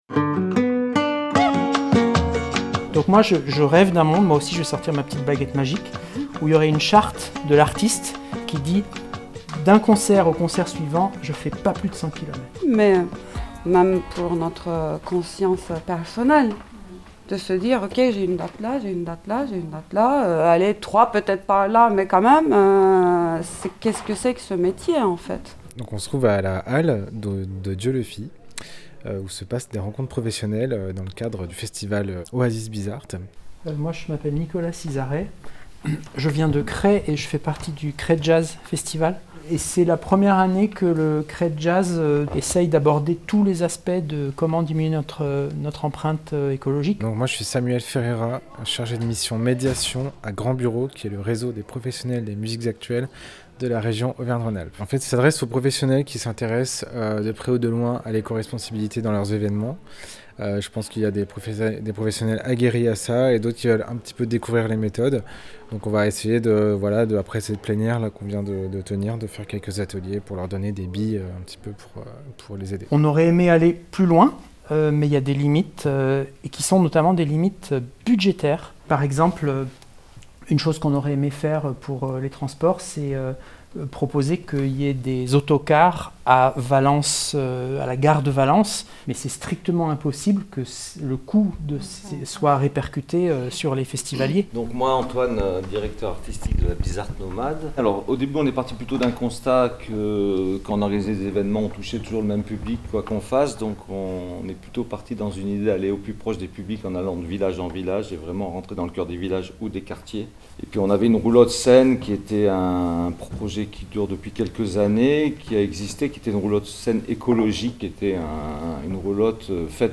7 juillet 2021 10:28 | Interview, radio bizz'art, Radio bizz'art 2021
Comment limiter l’impact environnemental du secteur évènementiel ? C’est pour tenter de répondre à cette interrogation qu’une journée de rencontres professionnelles s’est tenue à la Halle de Dieulefit, dans le cadre de l’édition 2021 du festival Oasis Bizz’Art.